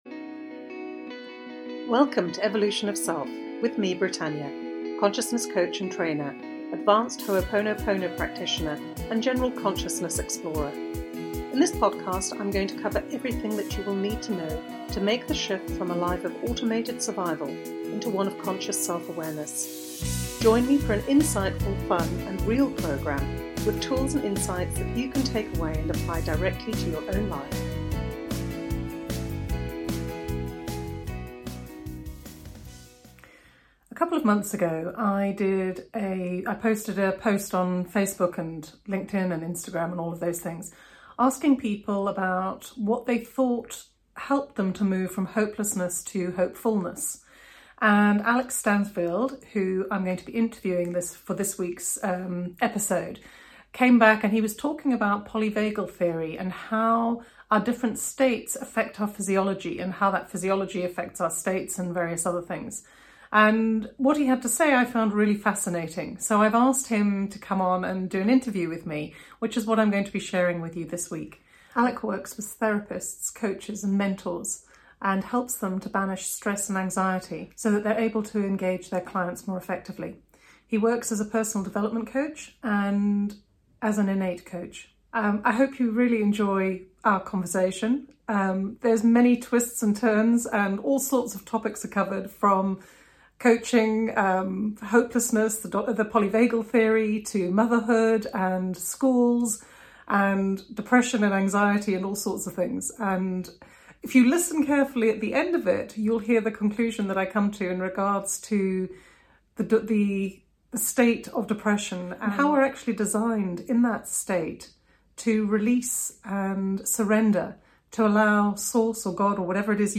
This episode is that interview. It was also during this interview that I realised how being hopeless actually is the portal to feeling hopeful and I share that at the end of this interview.